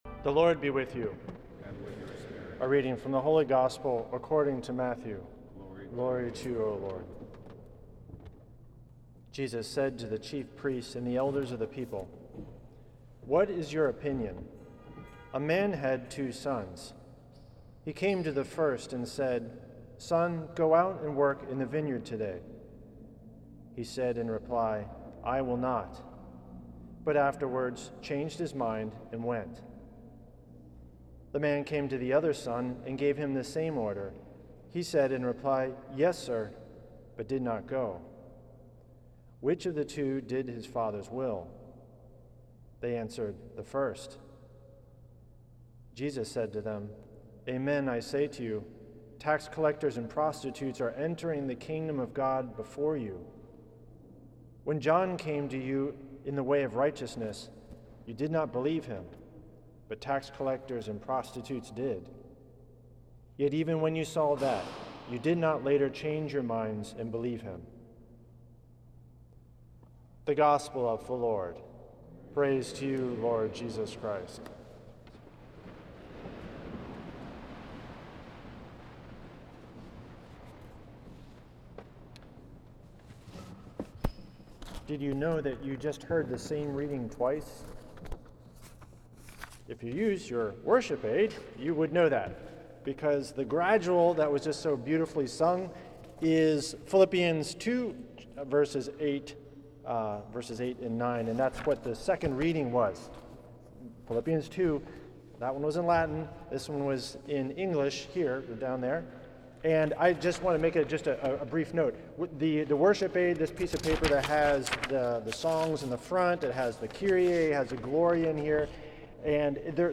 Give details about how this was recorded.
for the 11th Sunday in Ordinary Time at St. Patrick's Old Cathedral in NYC.